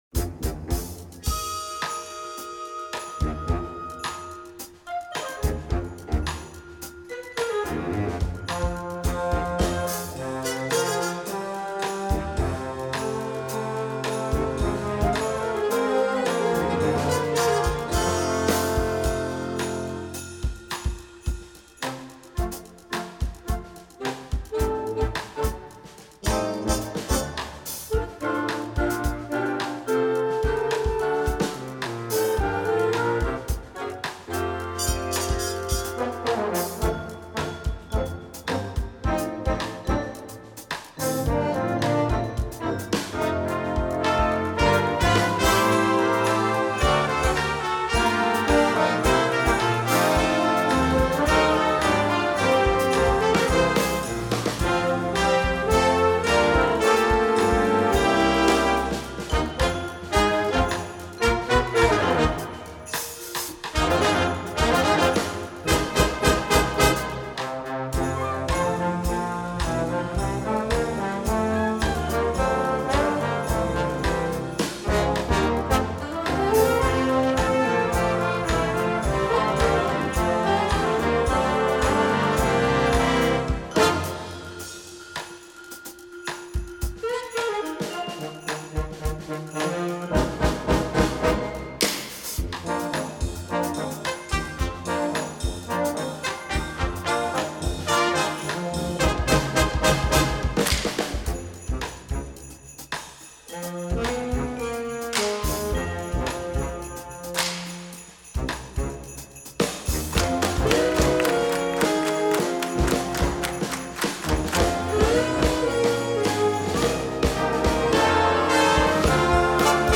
Gattung: Weihnachtsmusik für flexibles Blasorchester
Besetzung: Blasorchester